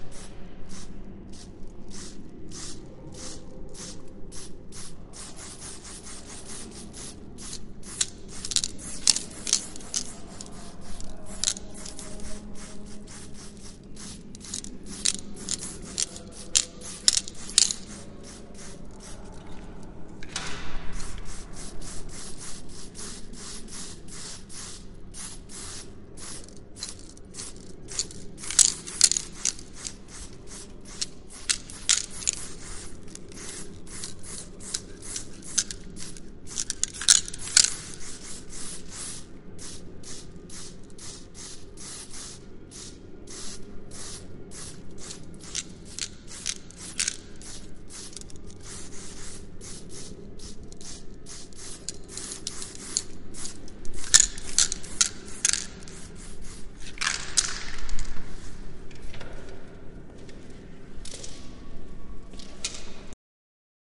graffiti_1.mp3